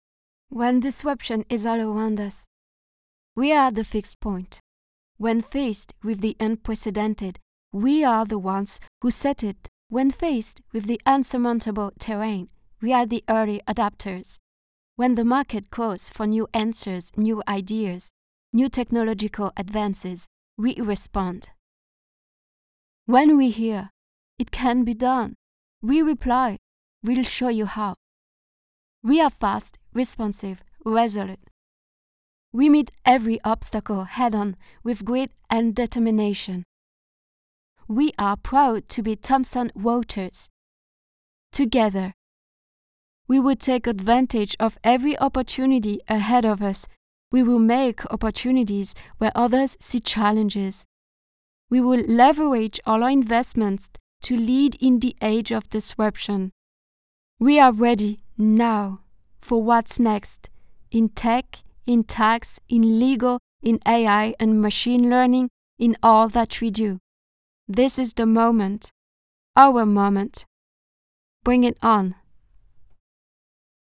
French accent, alsacien
I am a French singer and voice-over actress with a youthful, lively, and dynamic voice.